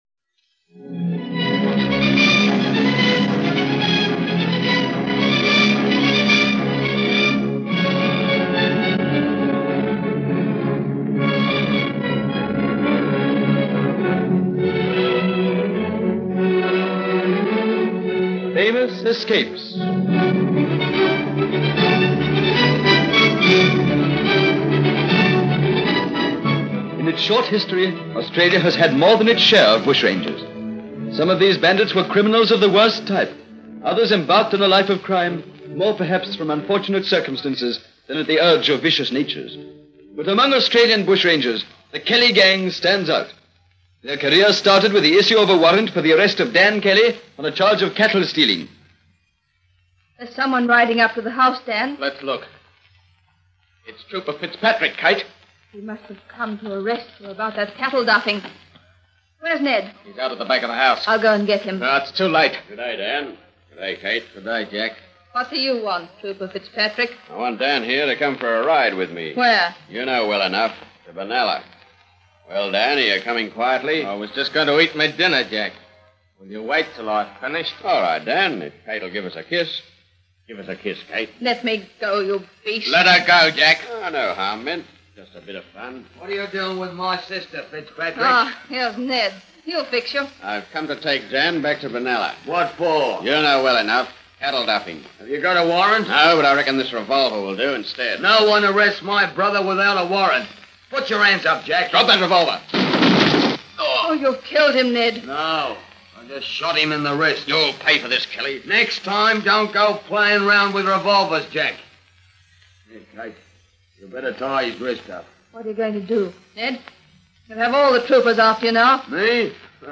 Famous Escapes was a captivating radio series produced in Australia around 1945.